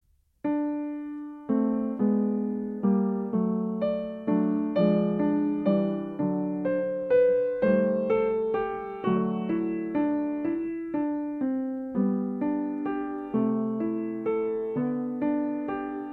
Nagrania dokonane na pianinie Yamaha P2, strój 440Hz